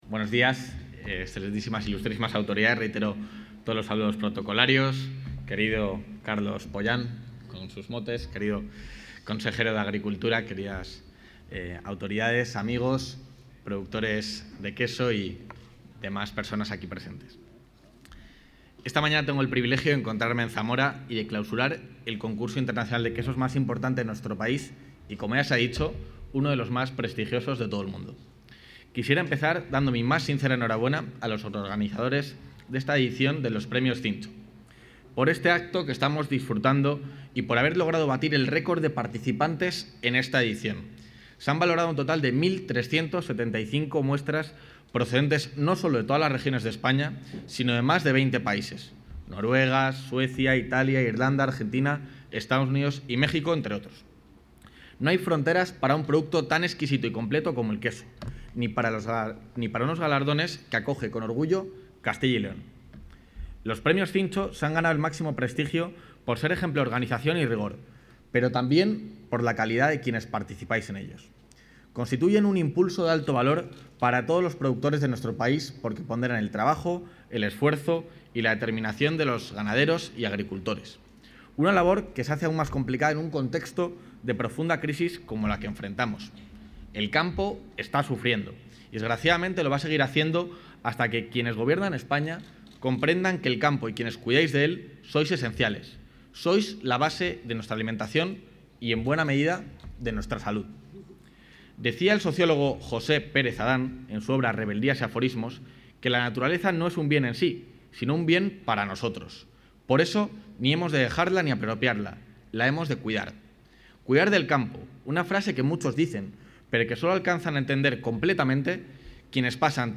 El vicepresidente de la Junta, Juan García-Gallardo, ha clausurado hoy la entrega de los Premios Cincho 2022, una cita que se ha consolidado...
Intervención del vicepresidente.